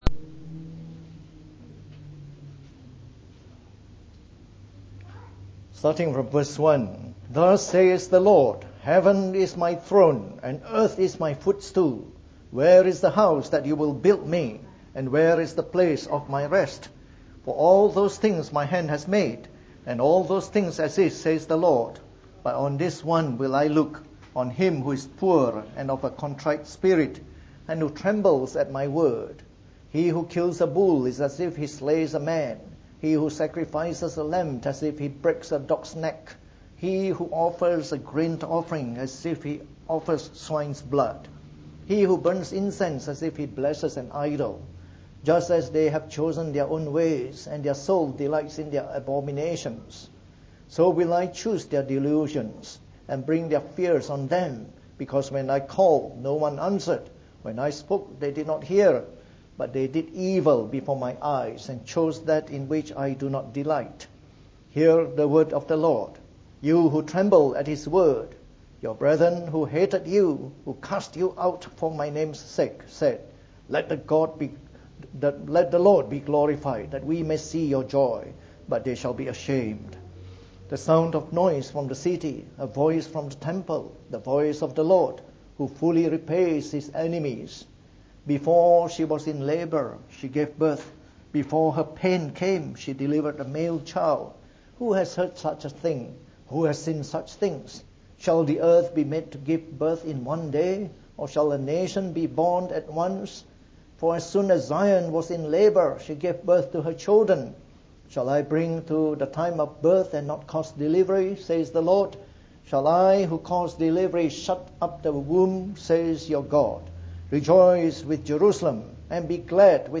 From our series on the Book of Isaiah delivered in the Morning Service.